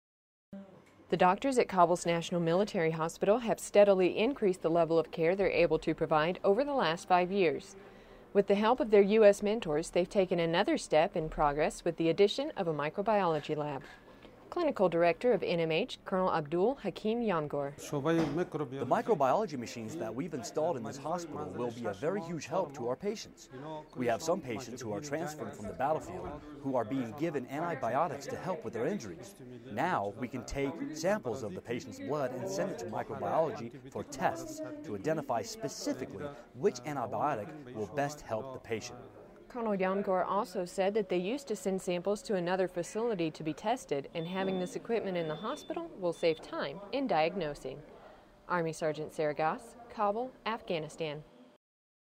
Radio package about how progress continues to be made at Afghanistan's National Military Hospital in Kabul.